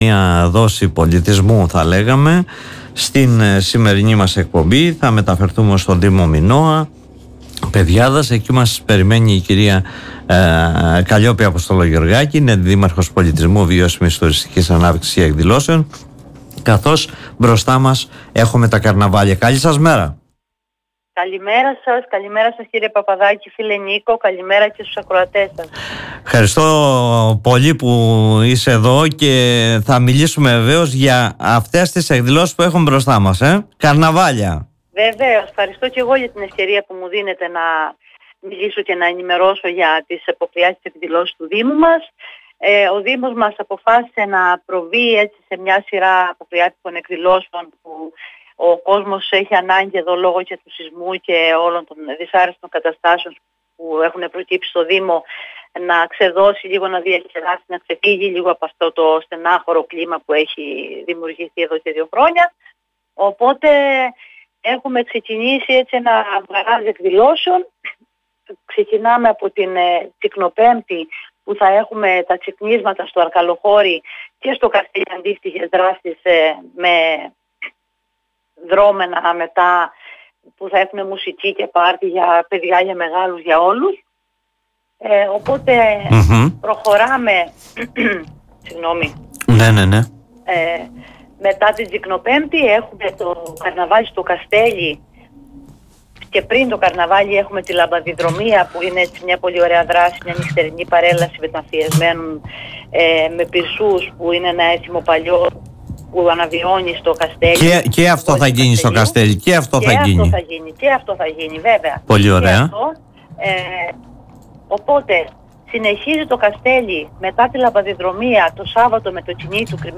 Κάλεσμα σε όλο τον κόσμο να λάβει μέρος στις αποκριάτικες εκδηλώσεις που διοργανώνει ο δήμος Μινώα Πεδιάδας απευθύνει η κ. Καλλιόπη Αποστολογιωργάκη αντιδήμαρχος Πολιτισμού, Βιώσιμης τουριστικής ανάπτυξης και εκδηλώσεων
Ακούστε την κ. Αποστολογιωργάκη: